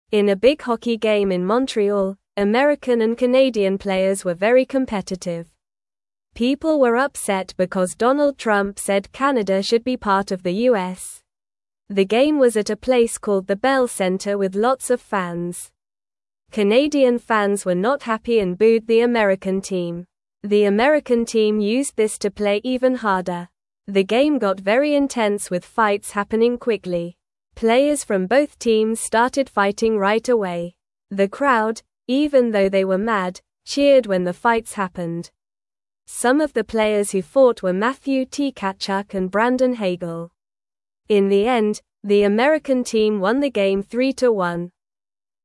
Normal
English-Newsroom-Beginner-NORMAL-Reading-Hockey-Game-with-Fights-and-Excited-Fans.mp3